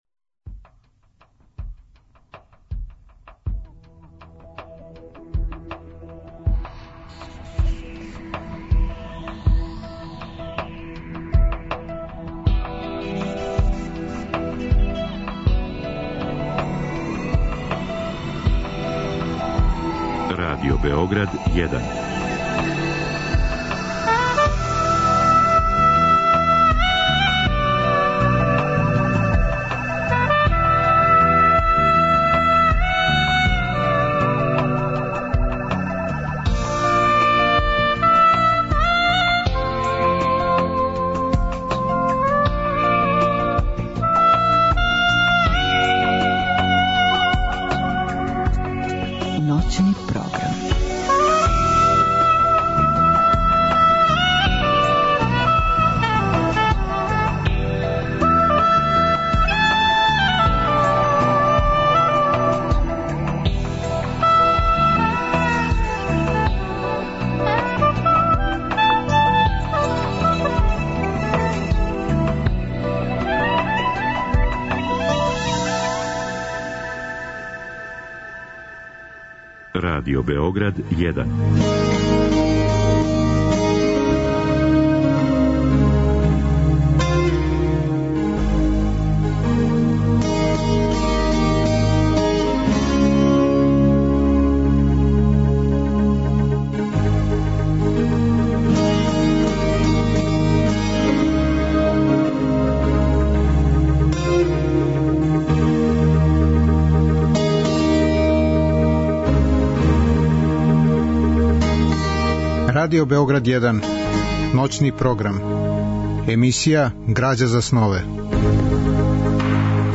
Разговор и добра музика требало би да кроз ову емисију и сами постану грађа за снове.
У ноћи између уторка и среде гост је писац Владимир Пиштало. Биће речи о односу реалности и фантастике у уметности, о томе како се у неким књижевним делима стварне личности и конкретни догађаји претапају у фантастичне призоре који личе на сан, као и о месту књижевности у данашњем времену.
Драма је реализована 1992. године у продукцији Драмског програма Радио Београда.